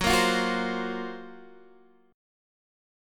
GbM#11 chord